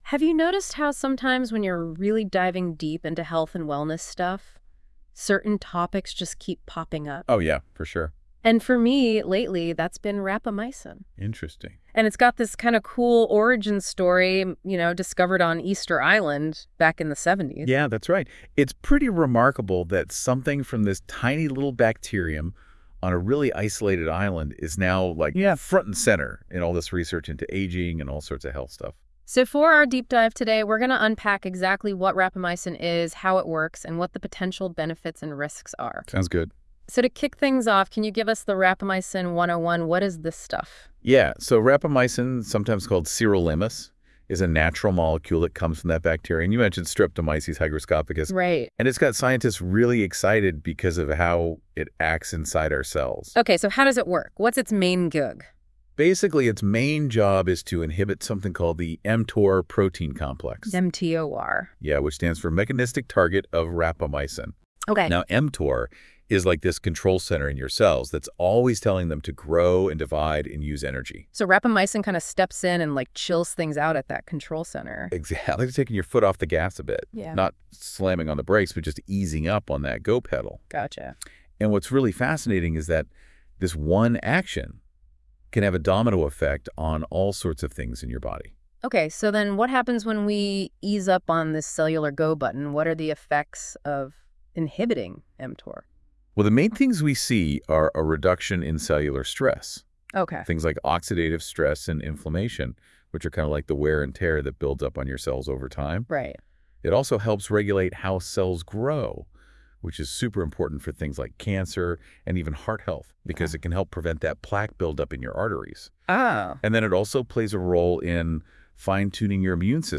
Is Rapamycin the key to healthy aging? Dive into our expert-led podcast interview to explore its potential benefits, risks, and how it fits into the latest anti-aging research.